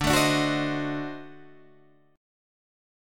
D7b9 chord